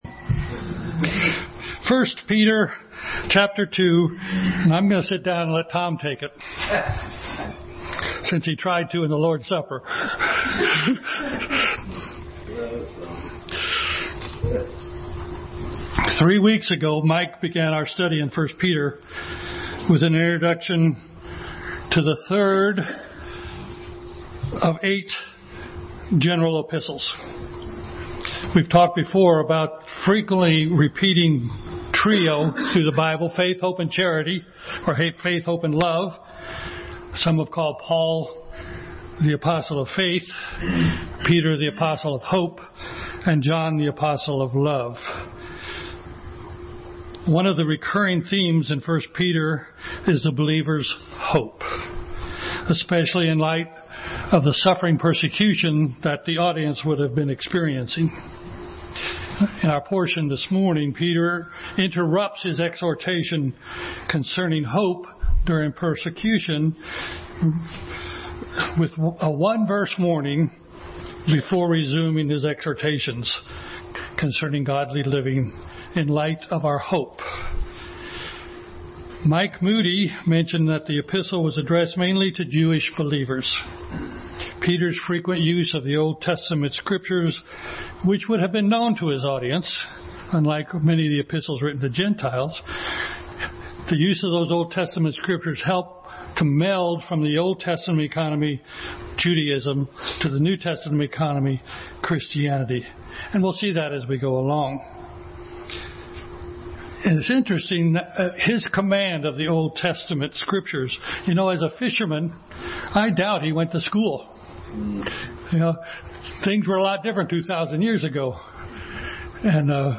1 Peter 2:1-10 Passage: 1 Peter 2:1-10, Ephesians 2:22, Romans 12:1, Hebrews 13:6, 15, Exodus 28:6, 1 Corinthians 3:10 Service Type: Sunday School